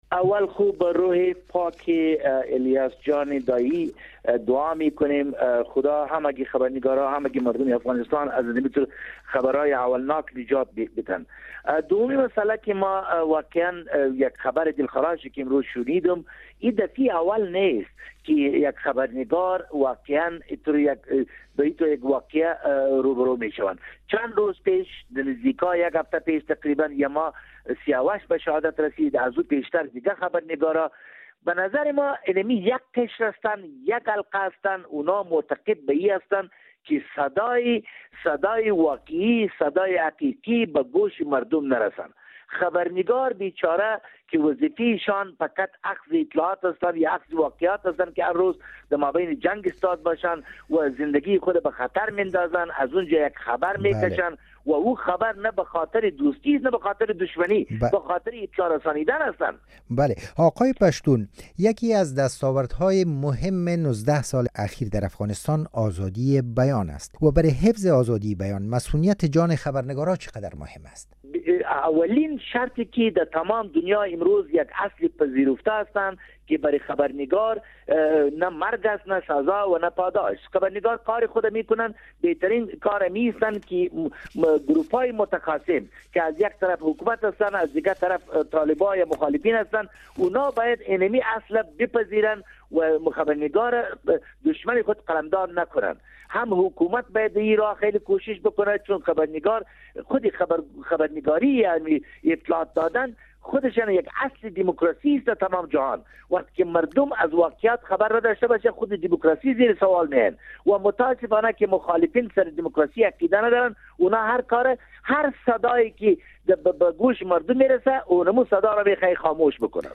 گفتگو با خالد پشتون، عضو پیشین ولسی جرگه افغانستان